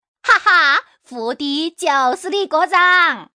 Index of /hunan_master/update/12814/res/sfx/changsha_woman/